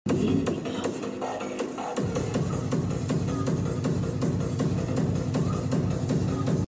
Illegal Outdoor Rave Vibes At Sound Effects Free Download